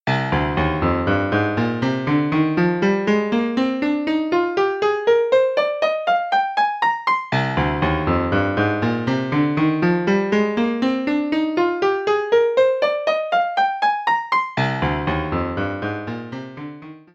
Die Funktion scale liefert einen Ring, der eine Molltonleiter enthält.
mollup.mp3